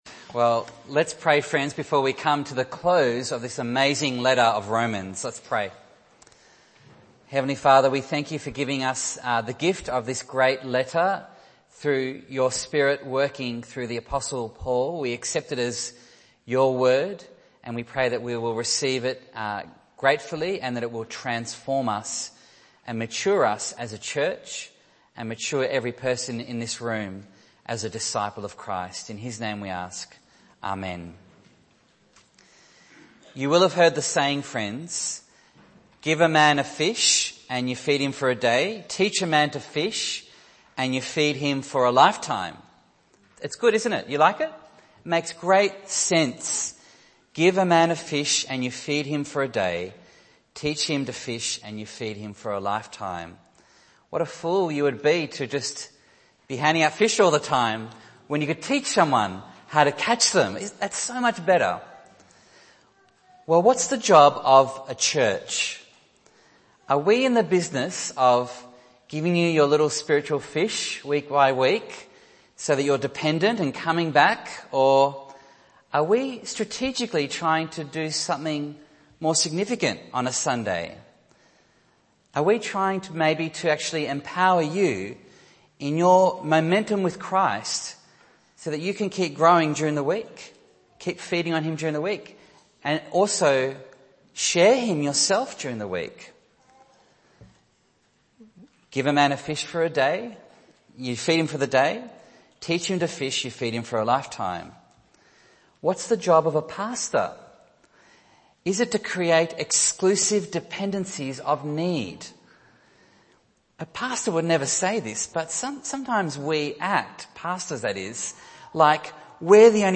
Bible Text: Romans 16:17-27 | Preacher